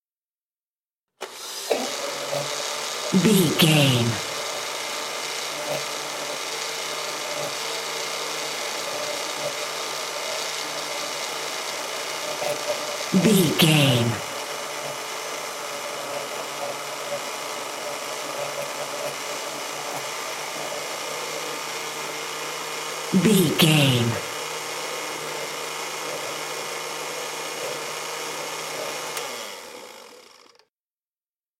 Blender
Sound Effects
house kitchen